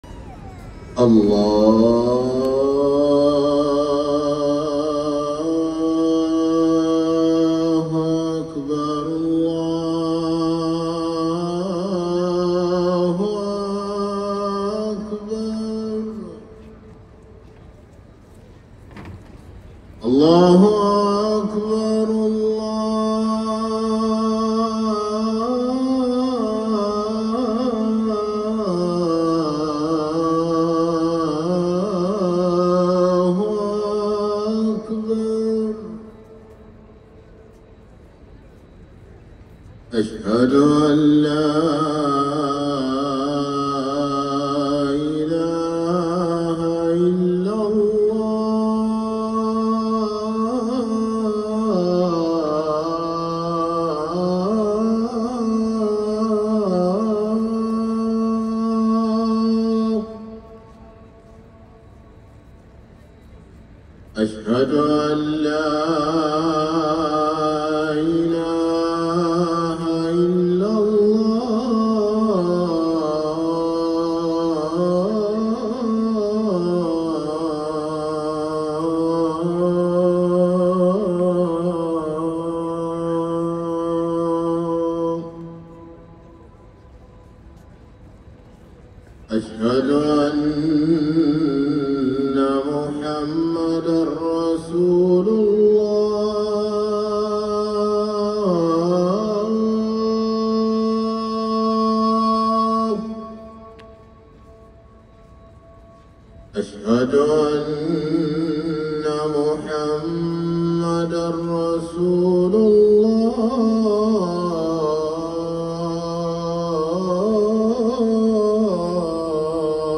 الأذان الأول لصلاة الفجر